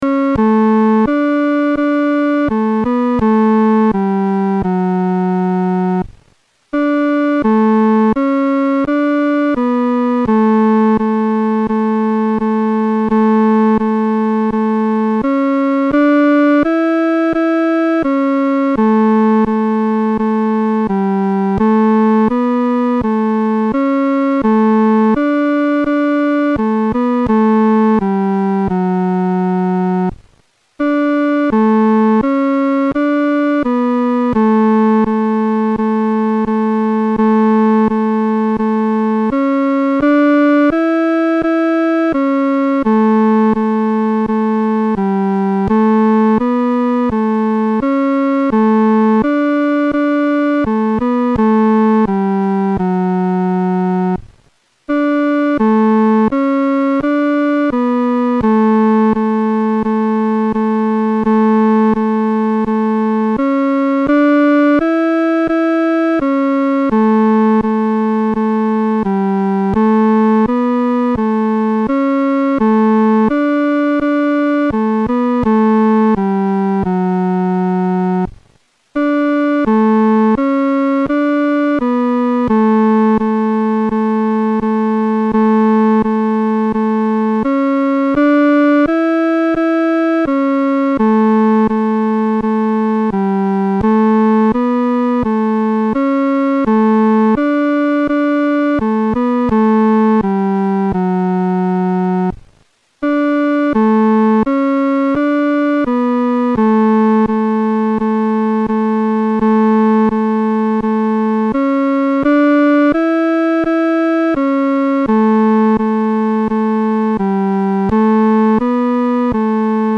伴奏
女高
这首诗的曲调活跃，和声变化色彩丰富；与前面五首赞美诗的和声处理方面很不同，是一首典型的“众赞歌”。